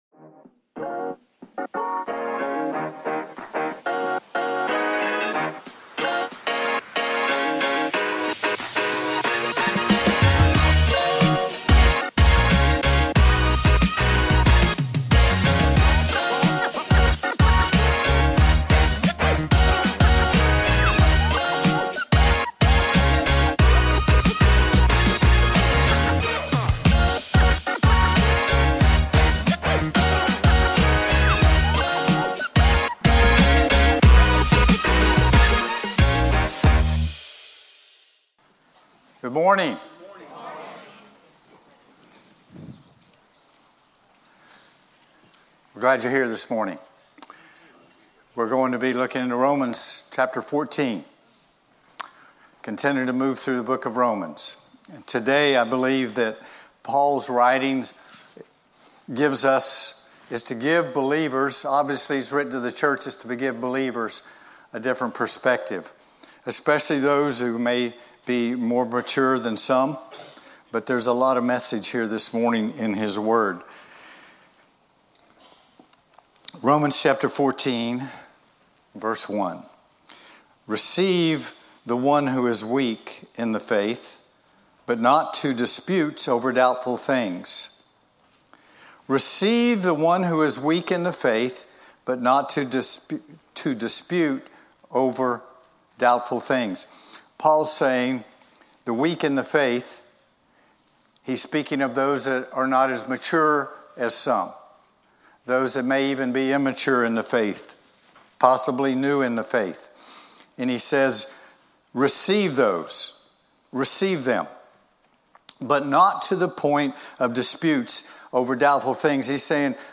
2026_04_12-Journey-Through-Romans-Part-23-Heartcry-Chapel-Sunday-Sermon.mp3